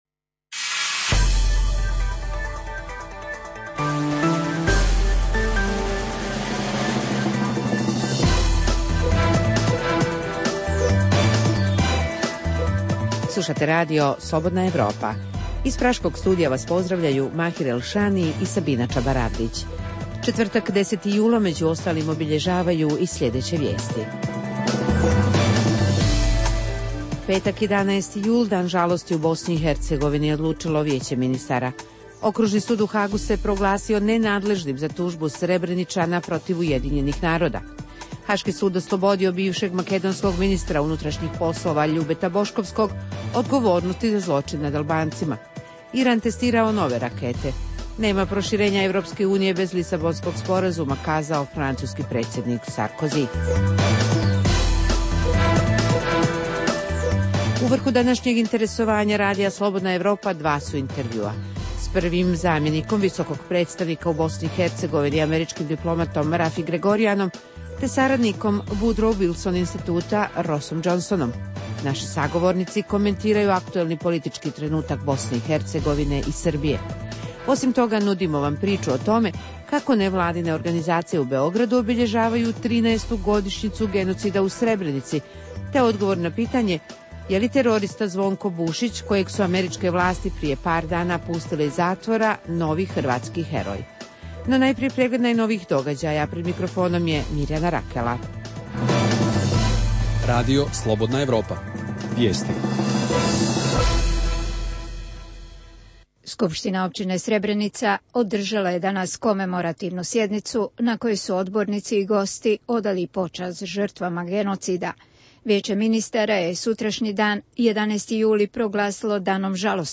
U vrhu današnjeg interesovanja Radija Slobodna Evropa su dva intervjua